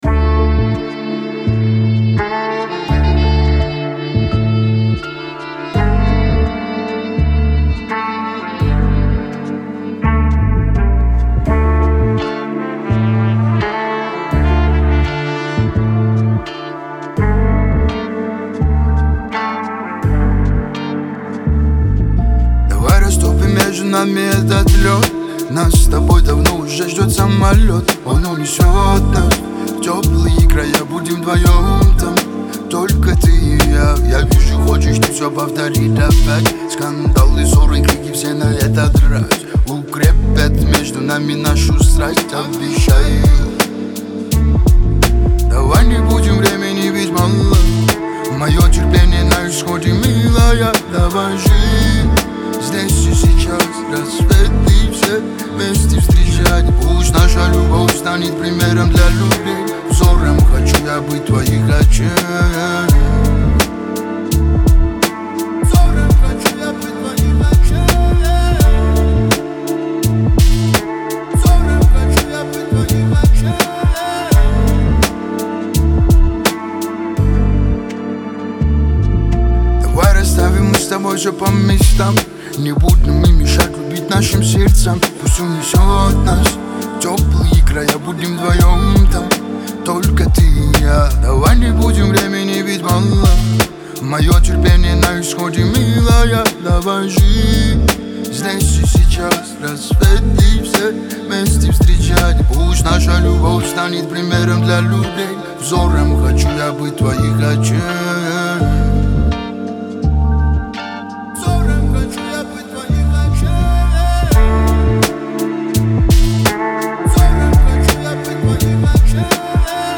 Лирика , Кавказ поп